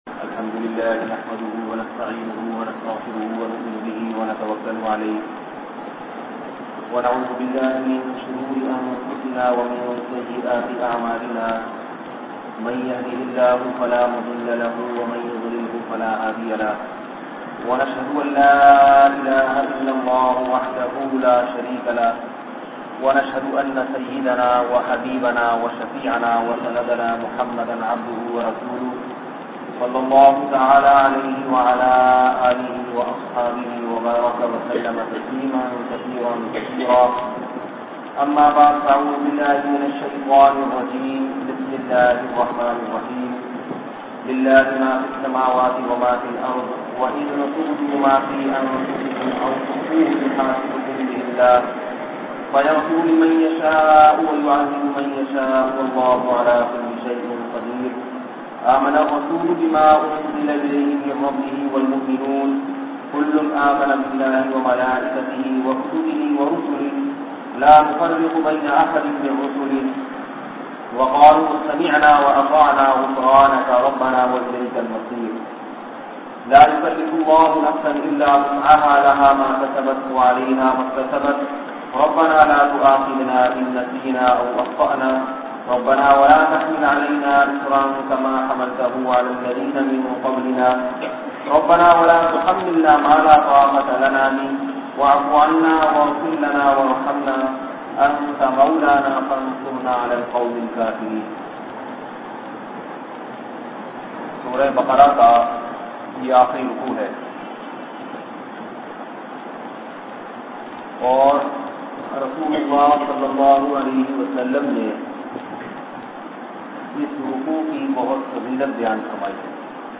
Farishtoo Ka Wajood bayan mp3